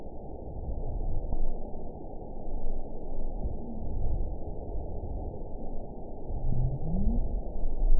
event 921701 date 12/16/24 time 22:48:31 GMT (11 months, 2 weeks ago) score 9.09 location TSS-AB03 detected by nrw target species NRW annotations +NRW Spectrogram: Frequency (kHz) vs. Time (s) audio not available .wav